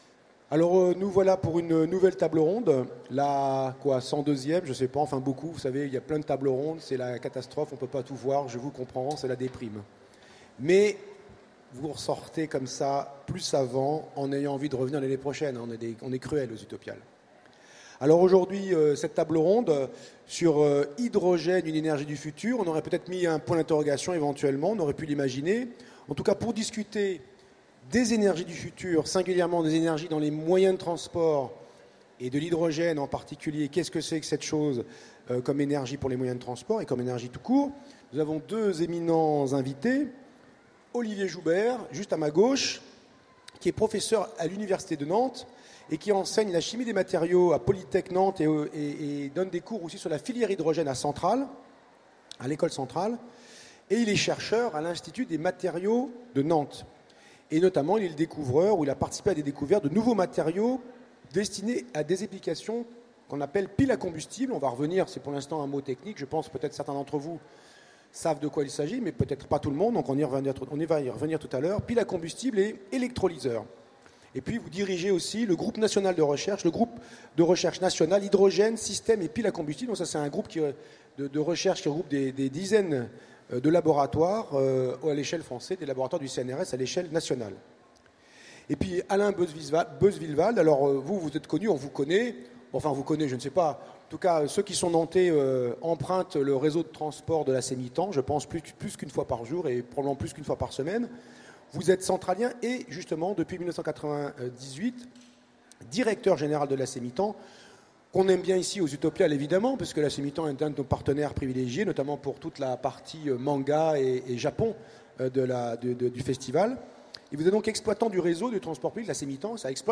Utopiales 2016 : Conférence L’hydrogène : une énergie du futur